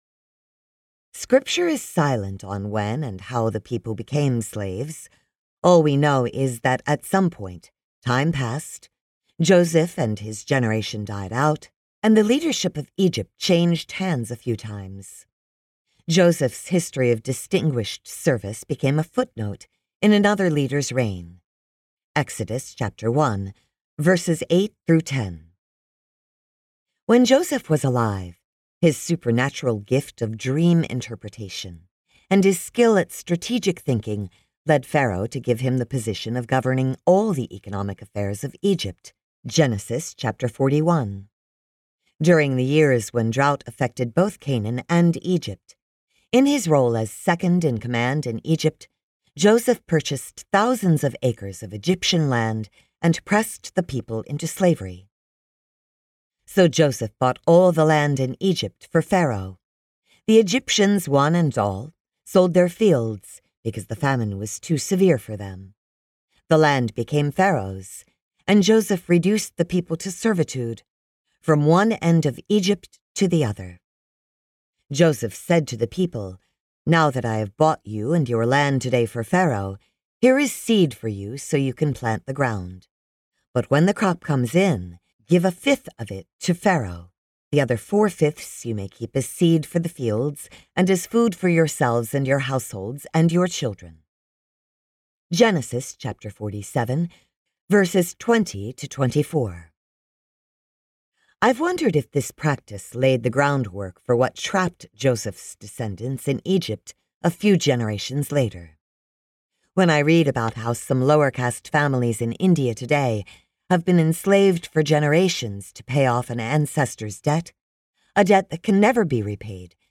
Born to Wander Audiobook
Narrator
4.33 hrs. – Unabridged